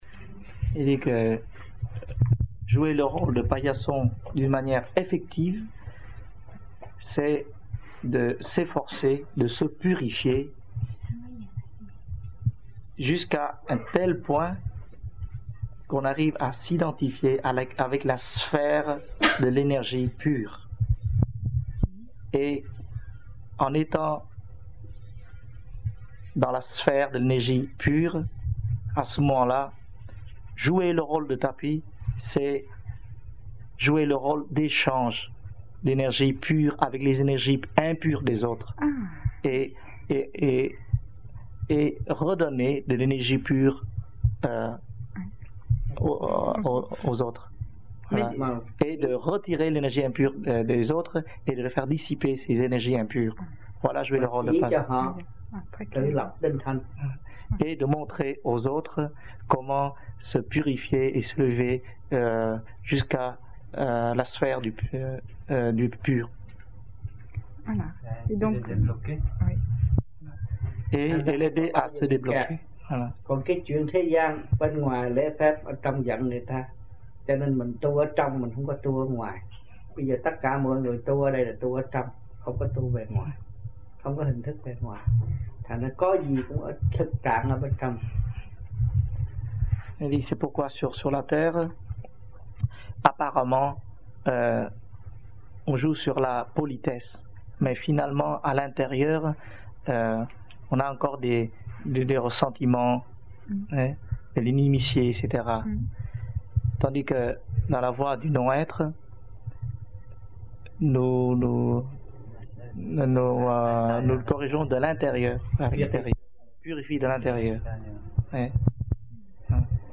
1980-11-20 - AMPHION - THUYẾT PHÁP 05